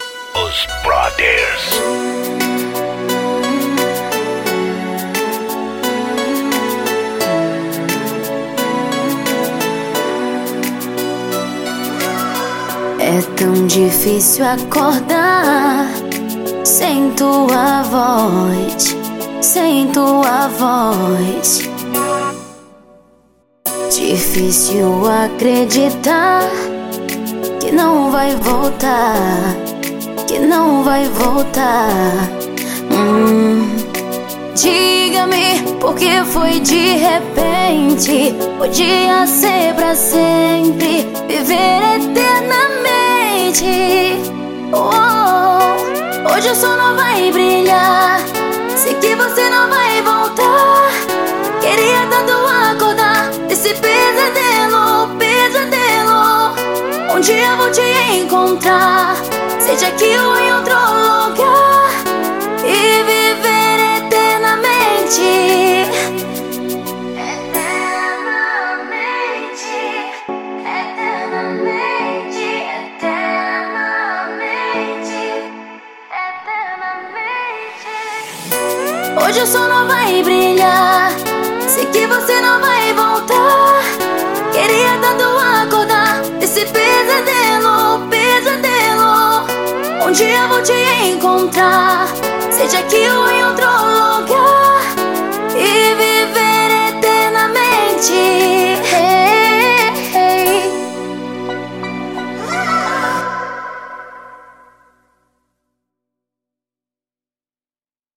OUÇA NO YOUTUBE Labels: Melody Facebook Twitter